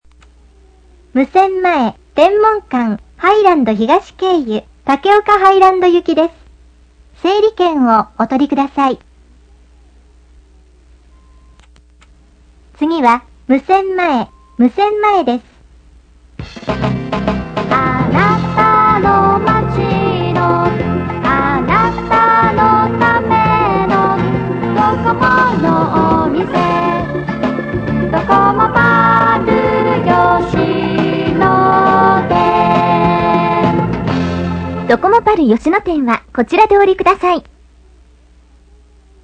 おまけ５８月からの南国交通。今回から案内がシステム化（継ぎはぎ）されました。